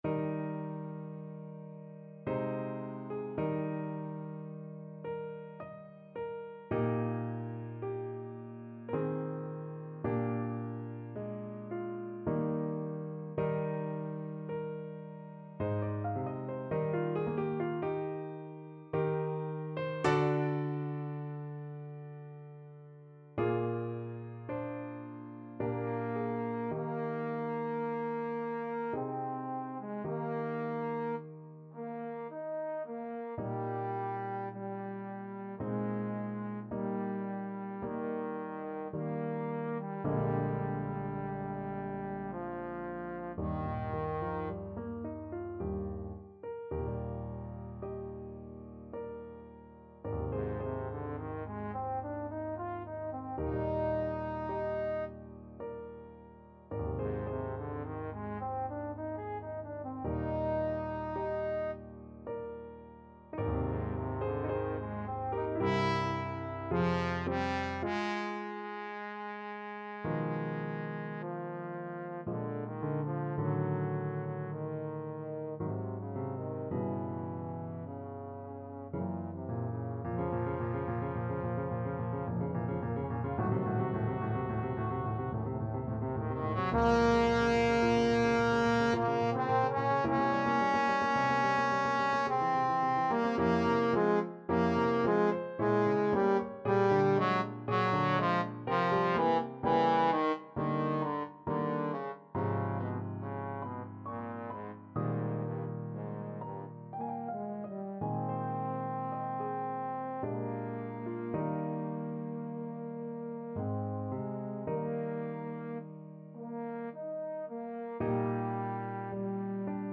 Trombone
3/4 (View more 3/4 Music)
Adagio ma non troppo =108
Eb major (Sounding Pitch) (View more Eb major Music for Trombone )
dvorak_cello_concerto_2nd_mvt_TBNE.mp3